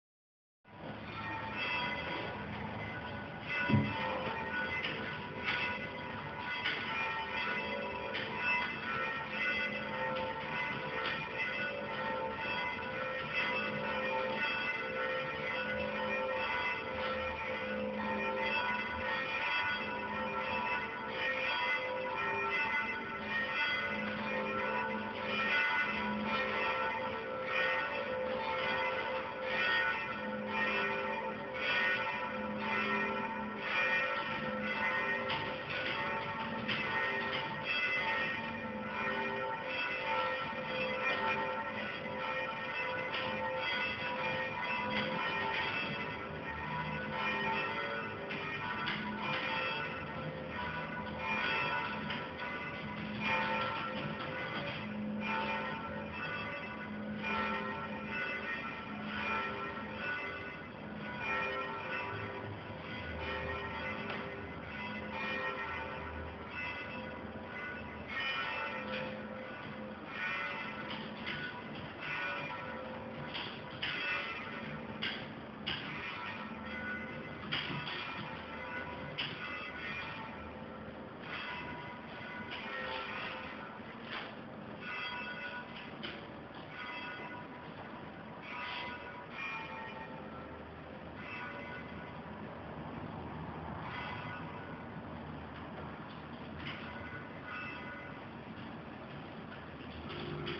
Missione Vibrazioni naturali: Cattura un suono caratteristico della città e documenta da dove proviene.
campane_20e_20tasti.mp3